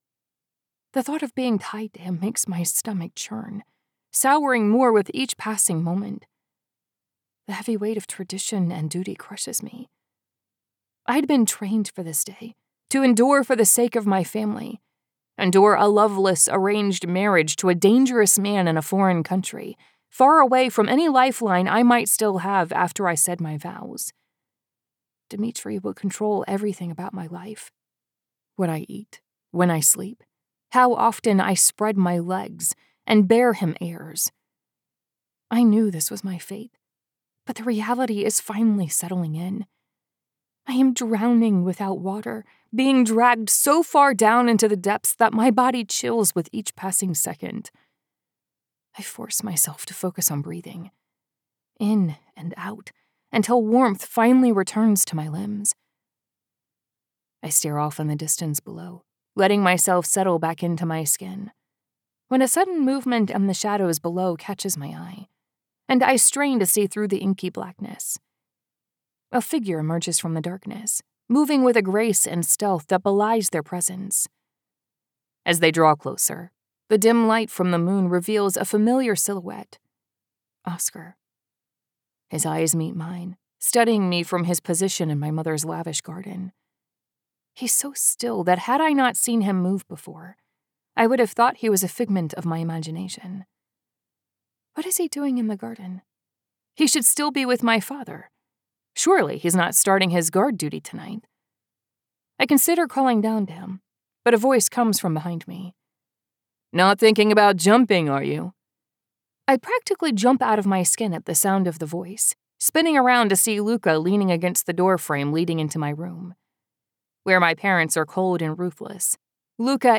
Narrator
Accent Capabilities: General American, Southern American.